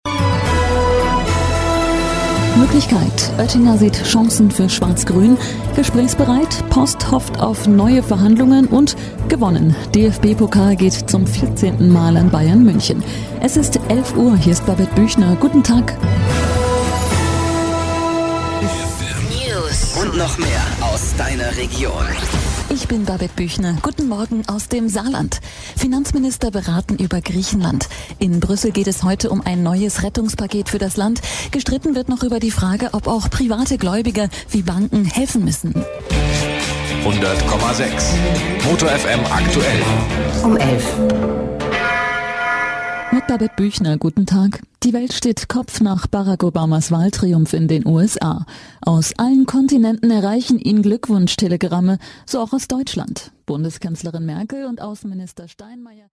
Wandelbare warme Stimme. Serios oder mit einem Lächeln auf den Lippen.
Sprechprobe: Sonstiges (Muttersprache):